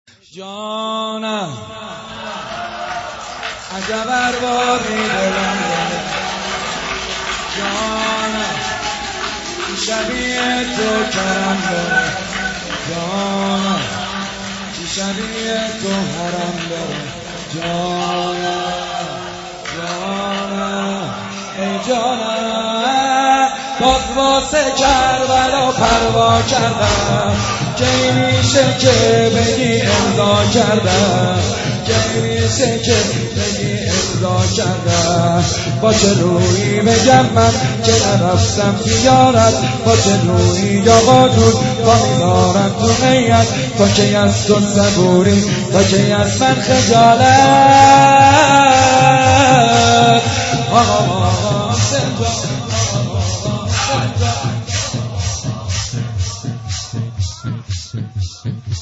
شور - جانم عجب اربابی دلم رو بردی